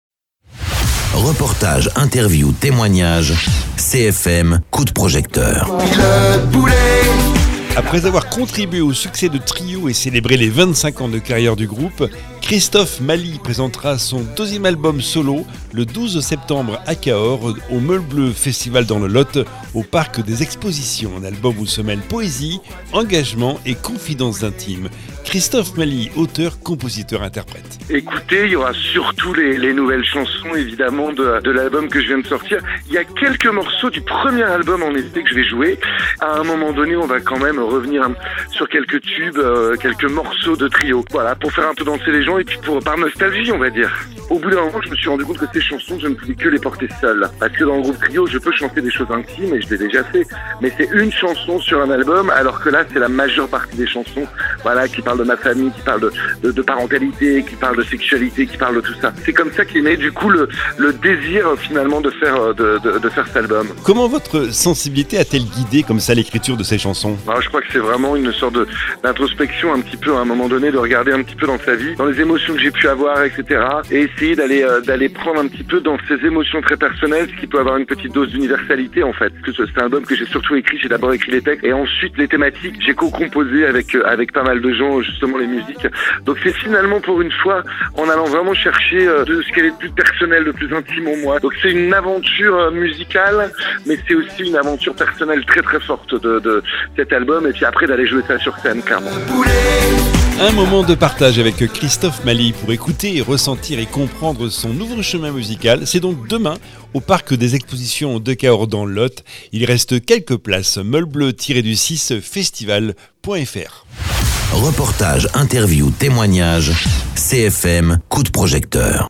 Interviews
Invité(s) : Christophe MALI, Auteur, Compositeur, Interprète